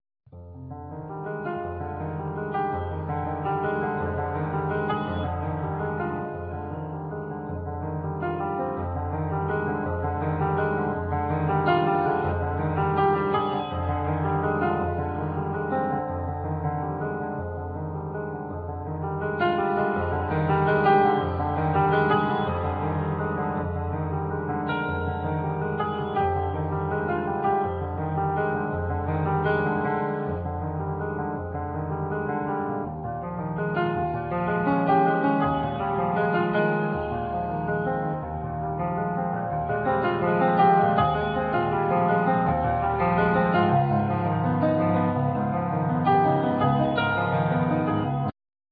Piano,Accordion
Double bass
Drums
Percussion
Saxophone
Acoustic and electric guitar
Voice
Txalaparta